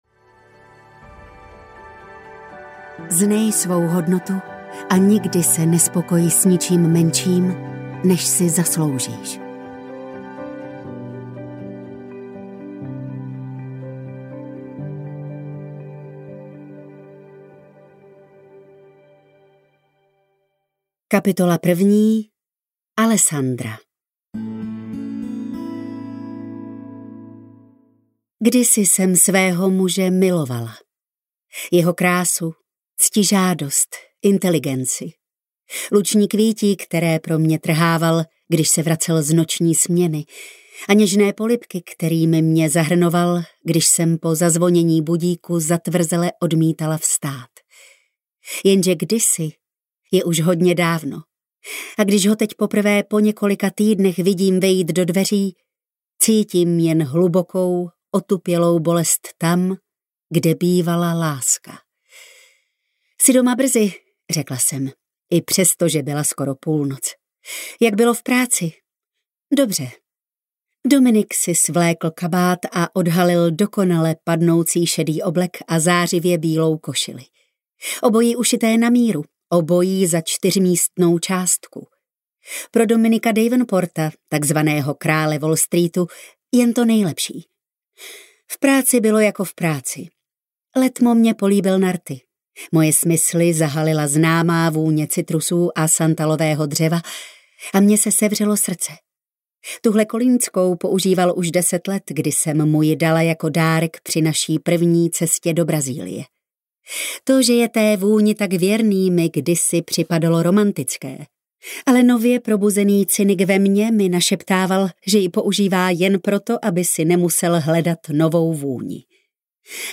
Král chamtivosti audiokniha
Ukázka z knihy